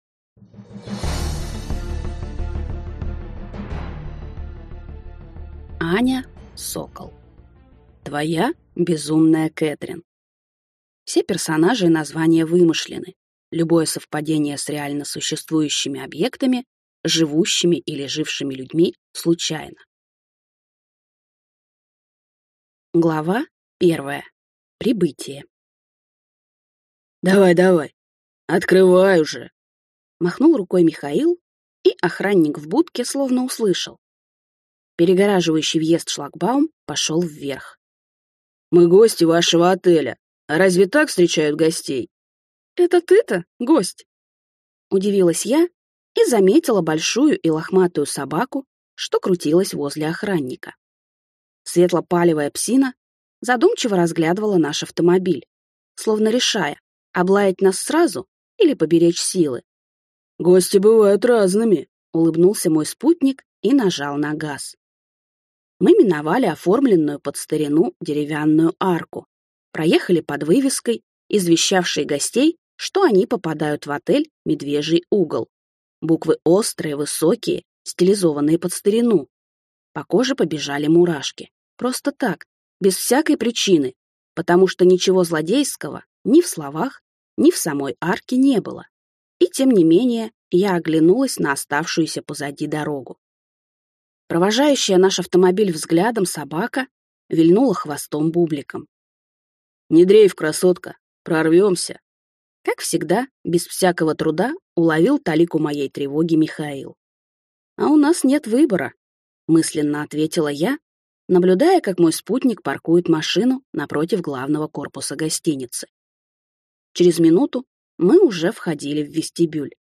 Aудиокнига Твоя безумная Кэтрин